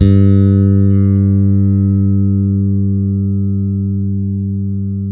-MM JAZZ G 3.wav